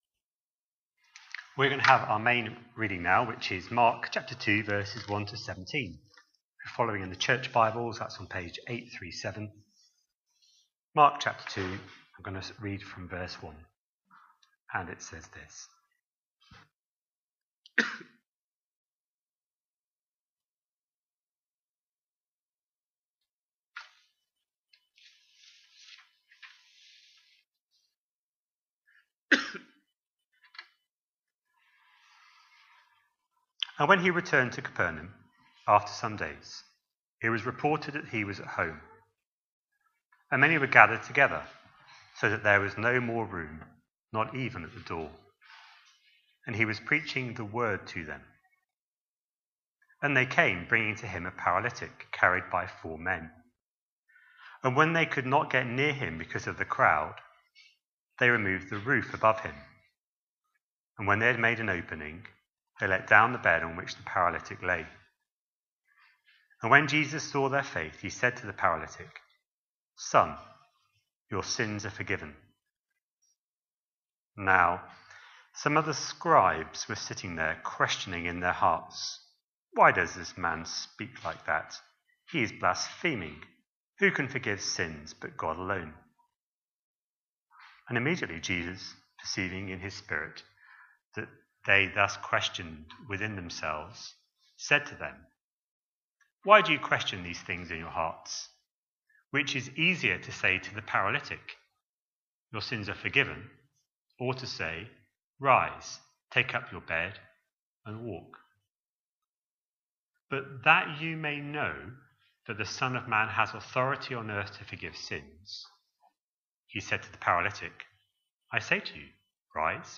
A sermon preached on 19th October, 2025, as part of our Mark 25/26 series.